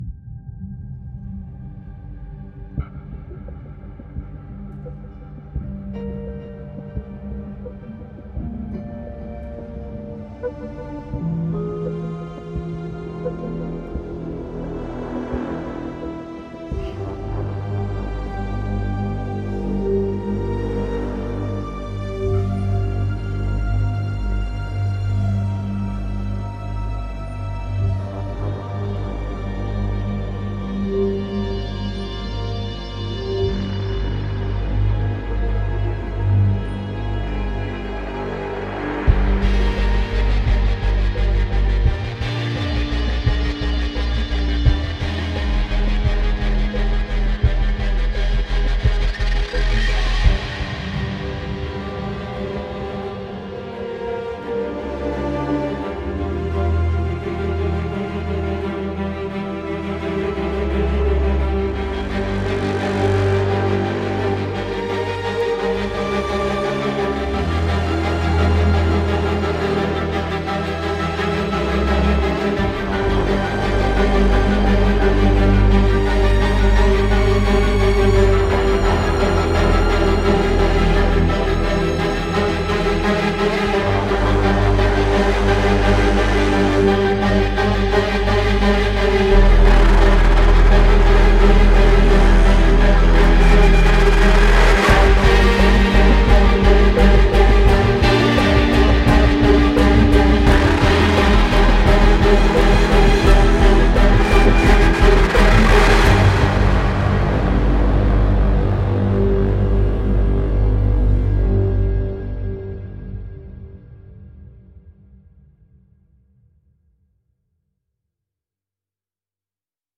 02-Spitfire-Audio-—-Ambient-Guitars.mp3